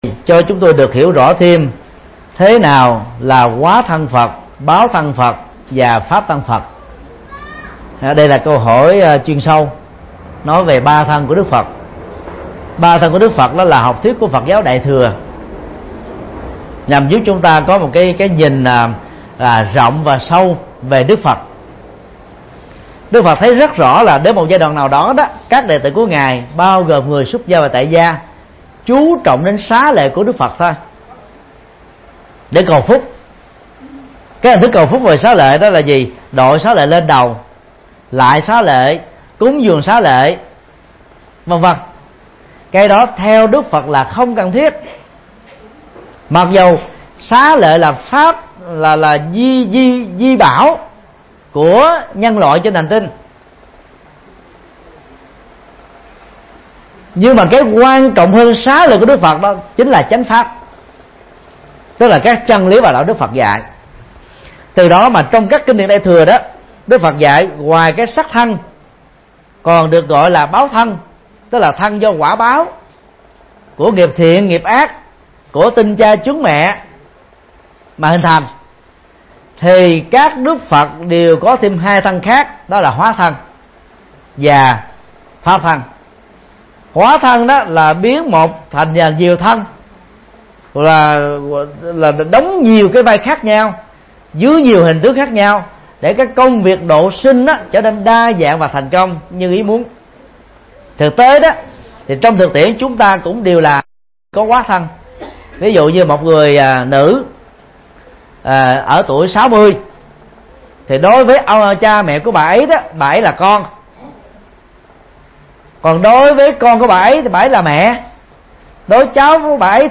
Vấn đáp: Ba thân Phật – Mp3 Thầy Thích Nhật Từ Thuyết Giảng